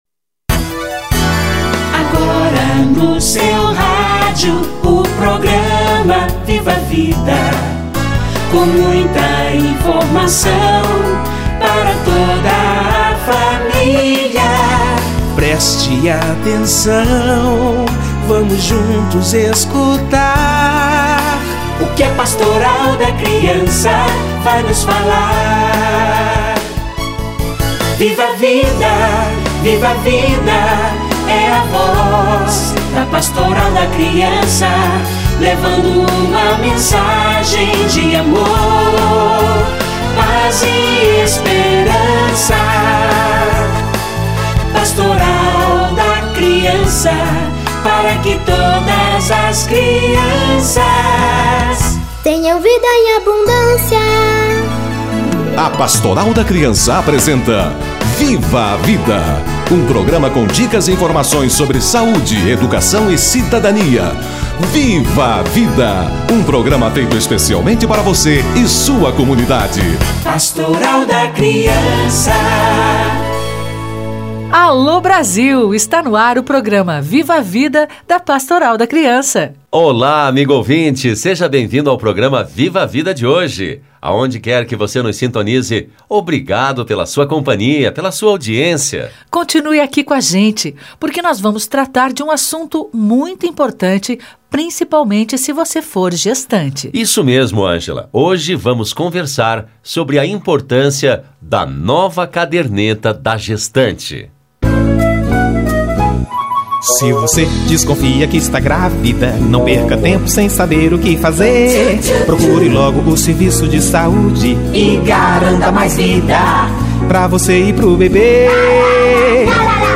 Mutirão em busca da gestante - Entrevista